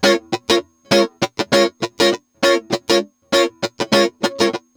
100FUNKY05-R.wav